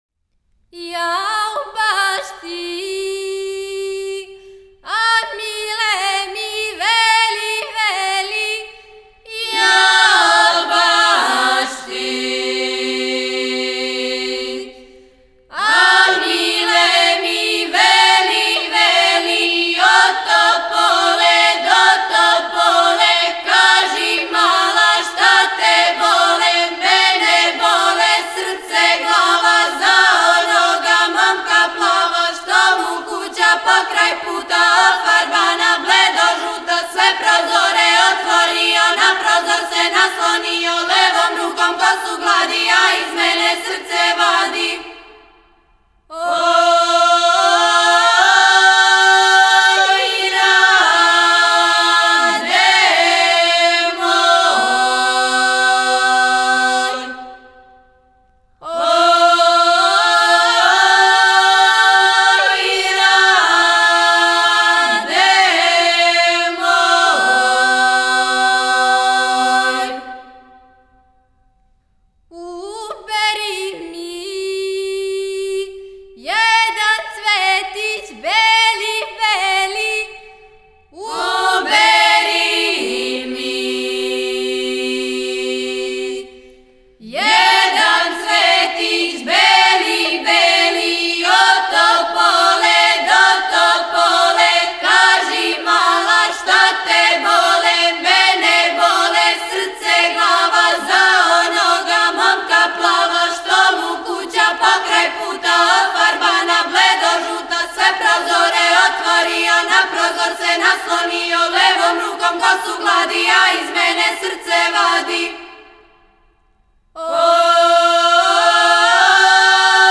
Порекло песме: Горња Црнућа, Рудник Начин певања: На бас. Напомена: Бројаница.